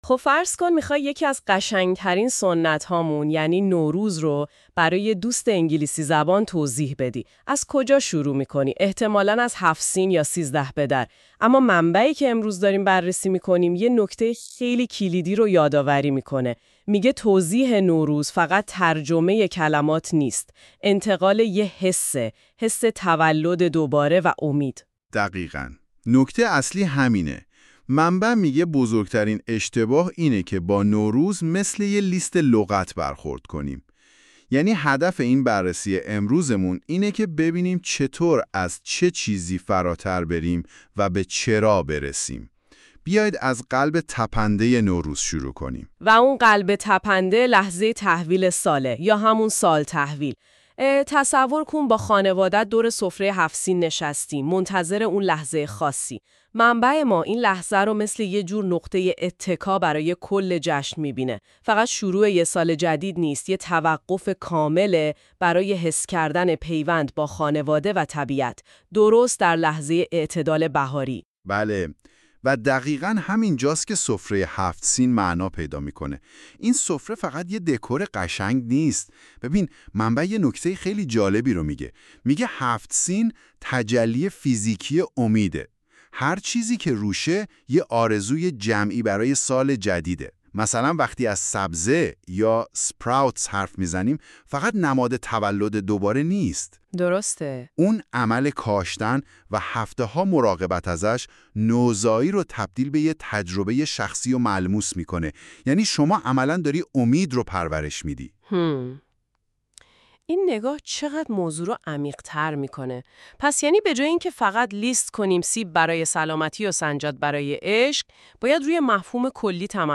nowruz-english-conversation.mp3